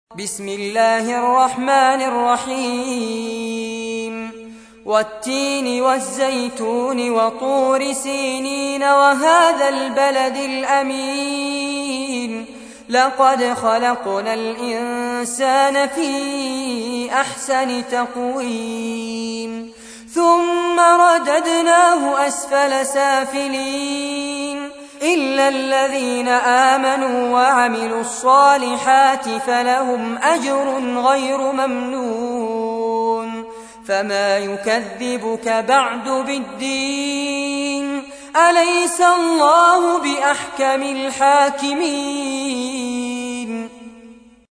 تحميل : 95. سورة التين / القارئ فارس عباد / القرآن الكريم / موقع يا حسين